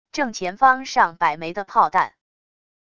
正前方上百枚的炮弹wav音频